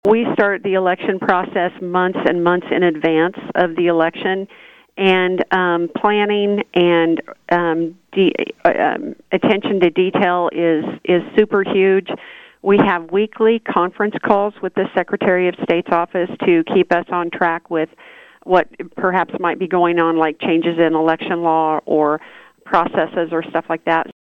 For county clerks like Lyon County’s Tammy Vopat, the election culminates a lengthy preparation period — as she detailed on KVOE’s Morning Show on Monday.